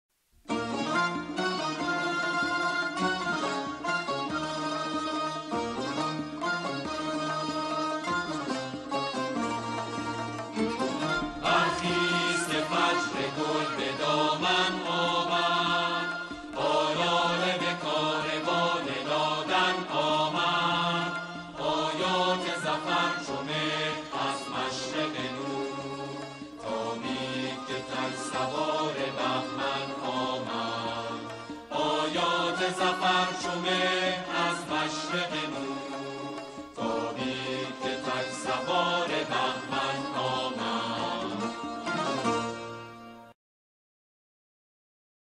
آنها در این قطعه، شعری را درباره دهه فجر همخوانی می‌کنند.